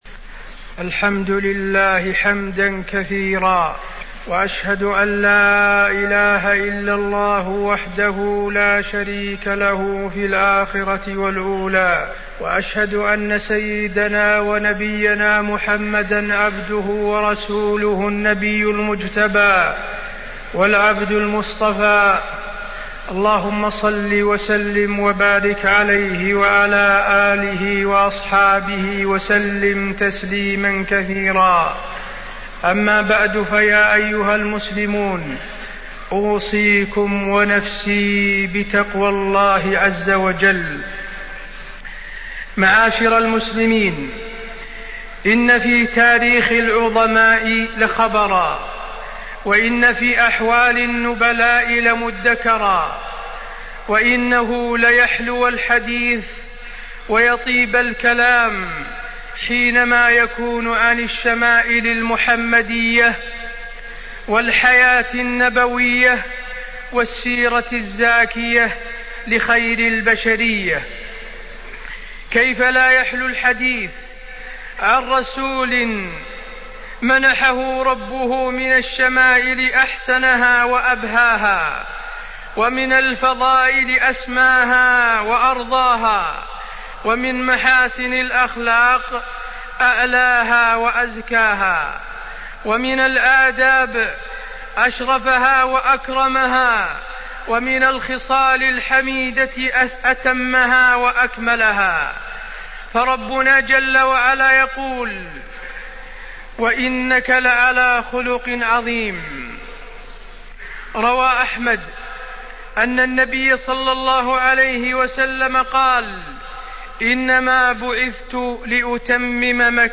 تاريخ النشر ١٢ شوال ١٤٢٧ هـ المكان: المسجد النبوي الشيخ: فضيلة الشيخ د. حسين بن عبدالعزيز آل الشيخ فضيلة الشيخ د. حسين بن عبدالعزيز آل الشيخ الشمائل المحمدية